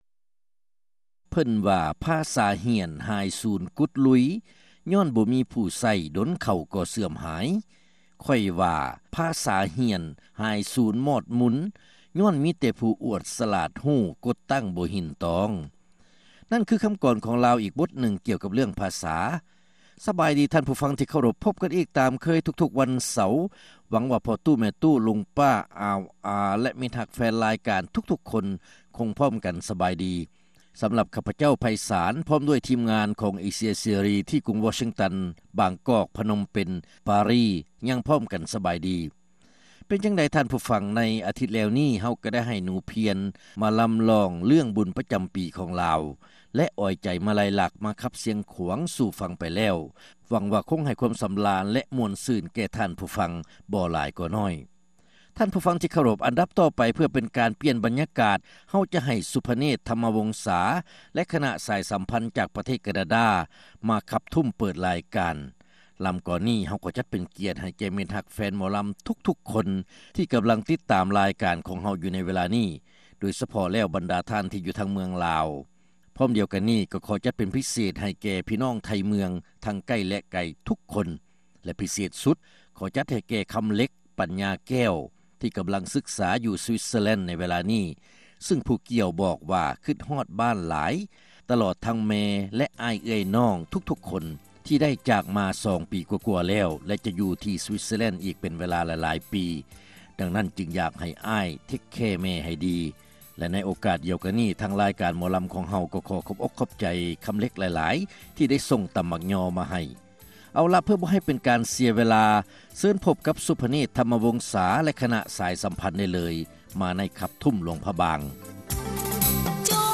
ຣາຍການໜໍລຳ ປະຈຳສັປະດາ ວັນທີ 10 ເດືອນ ພຶສະຈິກາ ປີ 2006